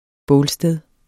bålsted substantiv, intetkøn Bøjning -et, -er, -erne Udtale [ ˈbɔːl- ] Betydninger sted der er indrettet til at man kan tænde bål, typisk til brug i forbindelse med madlavning vi bruger alle haven sindssygt meget.